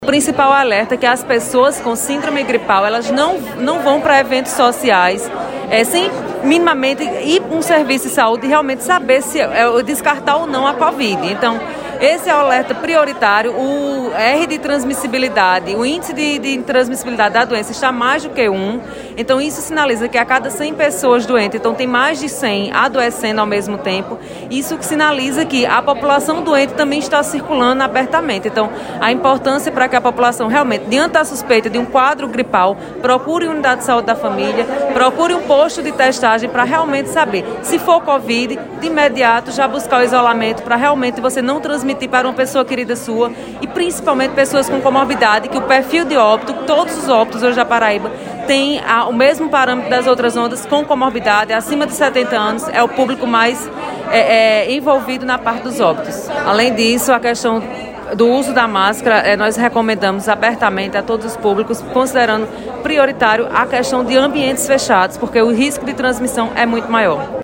Os comentários da secretária Estadual da Saúde foram registrados pelo programa Correio Debate, da 98 FM, de João Pessoa, nesta quinta-feira (01/12).